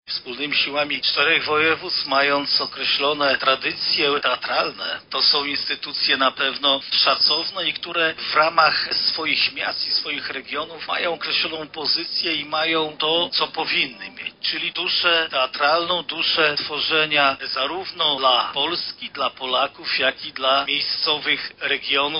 Klasyka 3.0, Marszałek Województwa Lubelskiego – Jarosław Stawiarski – mówi Marszałek Województwa Lubelskiego – Jarosław Stawiarski.